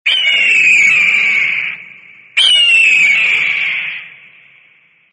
Hawk Ringtone
• Animal Ringtones